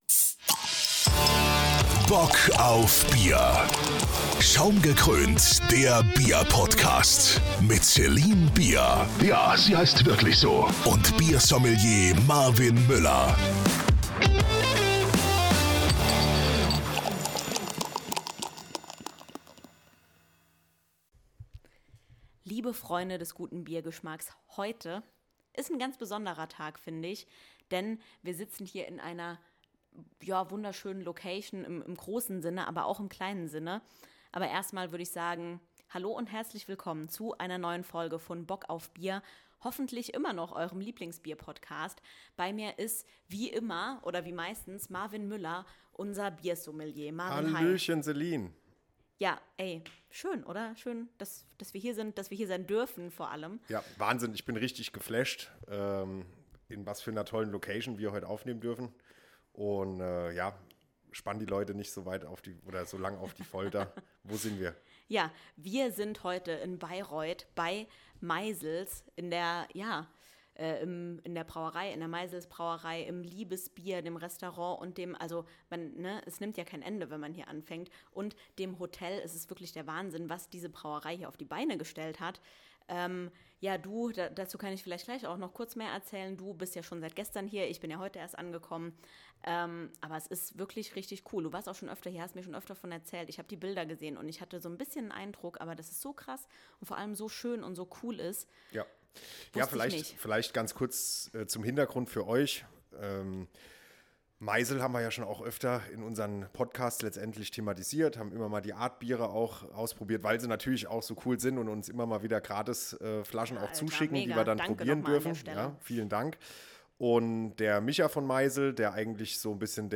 Uns wurde nämlich die Ehre zuteil, das Wochenende in Bayreuth auf dem Home Brew Event von der Maisel Brauerei zu verbringen.